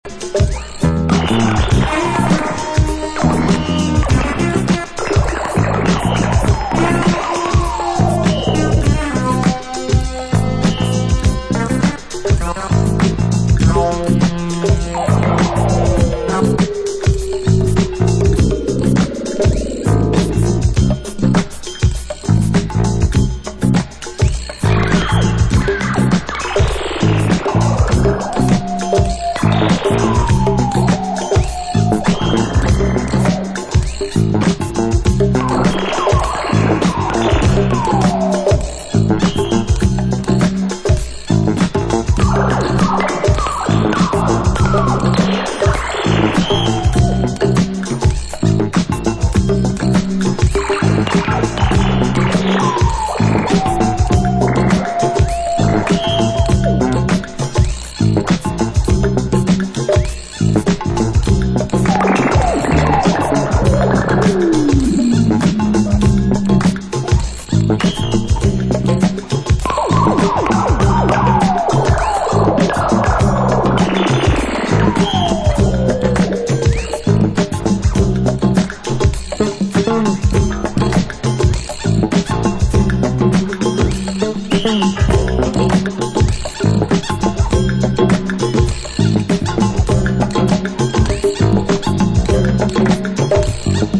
A mad classic with a loose groove and a spacey flying synth!